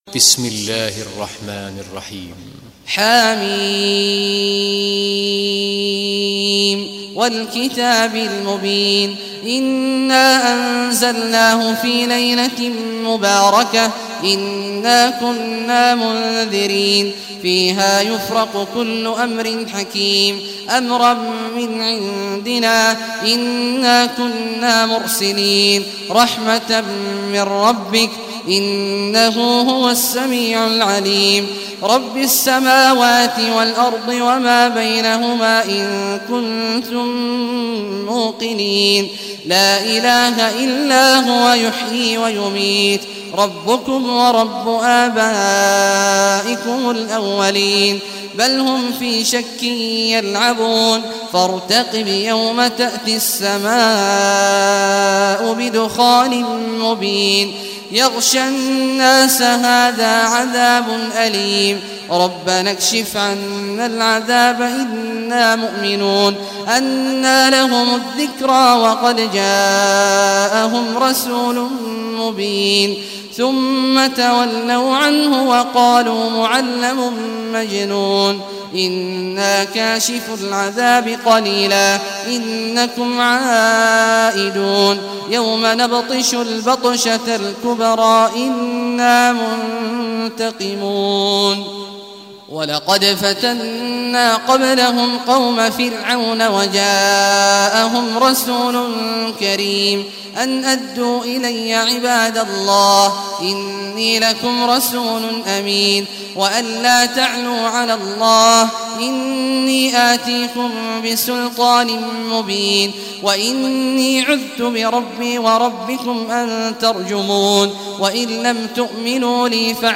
Surah Ad-Dukhan Recitation by Sheikh Awad Juhany
Surah Ad-Dukhan, listen or play online mp3 tilawat / recitation in Arabic in the beautiful voice of Sheikh Abdullah Awad al Juhany.